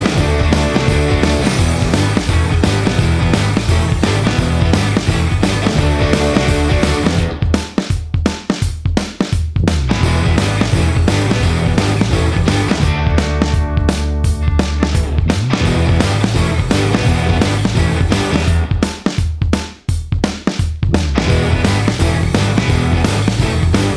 No Lead Guitar Rock 2:05 Buy £1.50